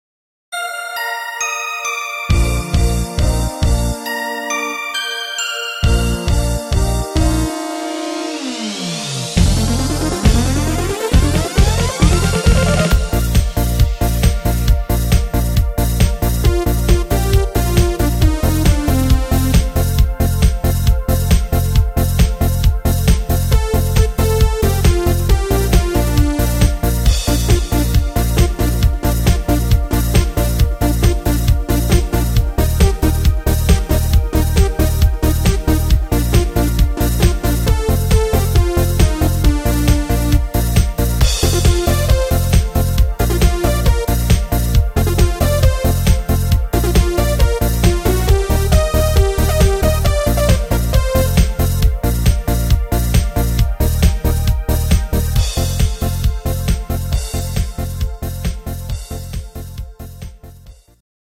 Party-Version